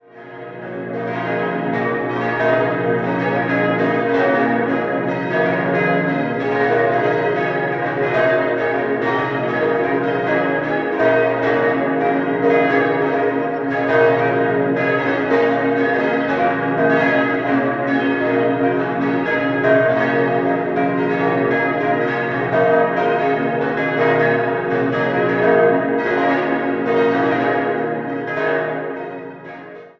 In der zweiten Hälfte des 15. Jahrhunderts wurde die Pfarrkirche mit dem 64 Meter hohen Turm errichtet. Nach einer Barockisierung wurde die Ausstattung zwischen 1850 und 1860 wieder regotisiert. 6-stimmiges Geläut: b°-des'-es'-f'-as'-b' Die fünf größeren Glocken stammen von Karl Czudnochowsky aus dem Jahr 1949. Die kleinste ergänzte Grassmayr im Jahr 2009.